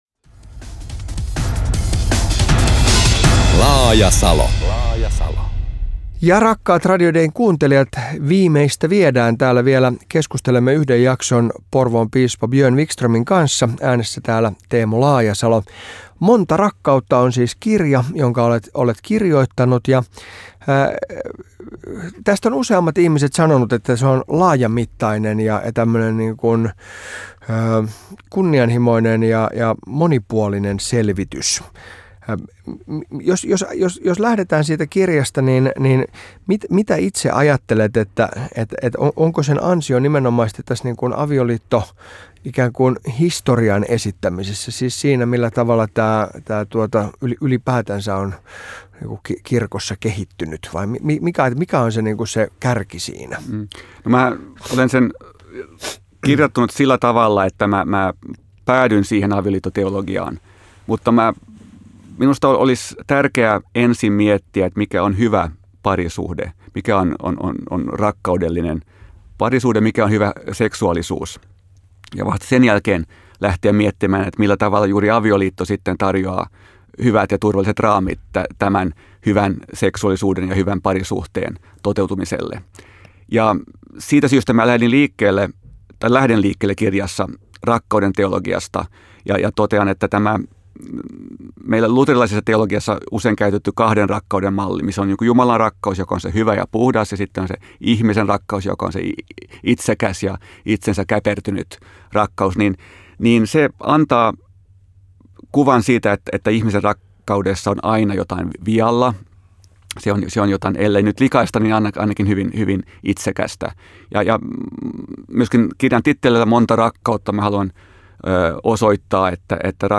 Piispojen näkökulma asioihin on monesti hyvin paikallinen, mutta Porvoon piispan kohdalla tilanne on toinen: hiippakunta on Suomen laajin, mutta toisaalta väkimäärältään pienin. Laajasalo keskustelee Monta rakkautta -kirjan kirjoittaneen piispa Björn Vikströmin kanssa muun muassa avioliittokysymyksestä.